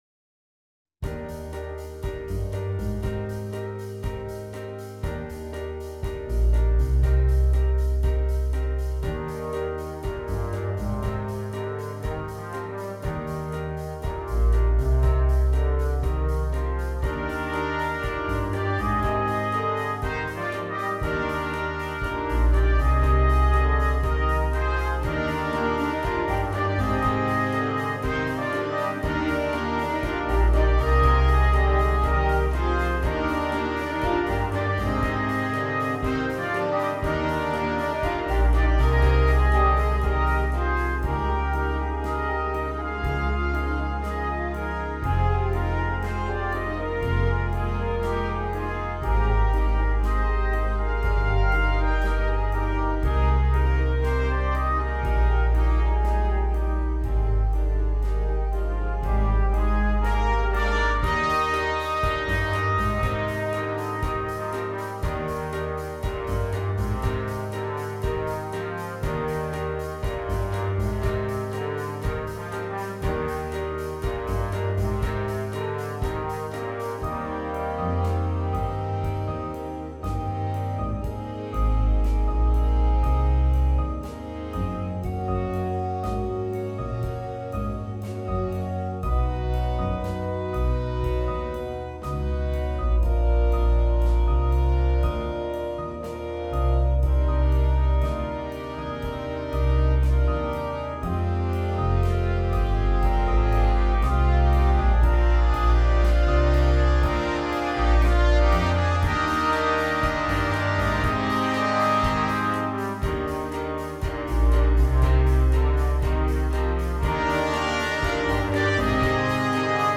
Jazz Band